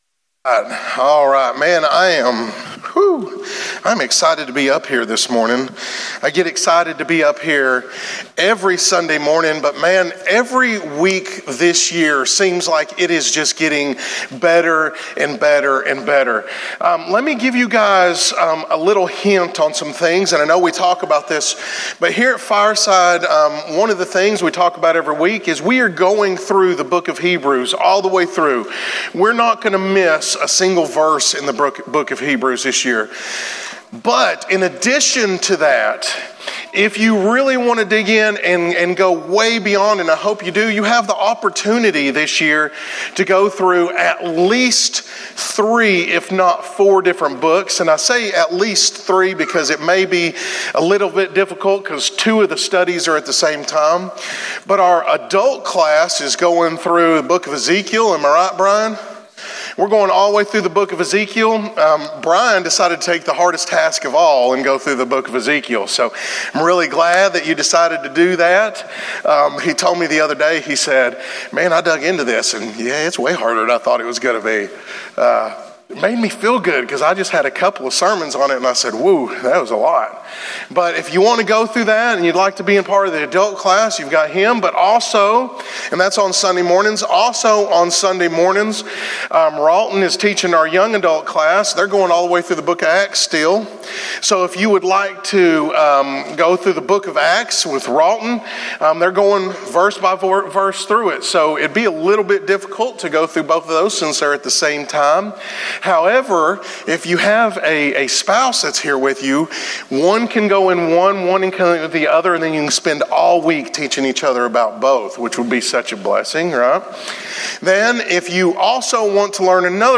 Recent Sermons
sermon.cfm